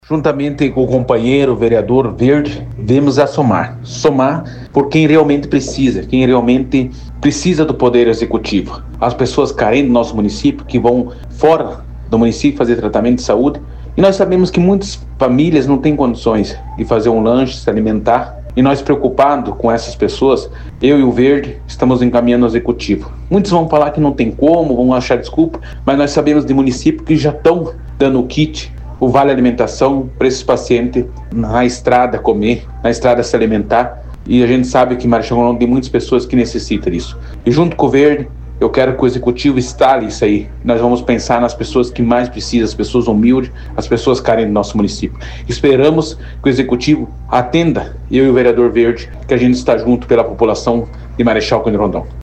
Ouça entrevistas dos vereadores, defendendo a proposta
O vereador Gordinho do Suco defende também a criação propõem vale-alimentação ou kit lanche para pacientes rondonenses que viajam para tratamento médico. ……..